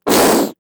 firebalhitl.mp3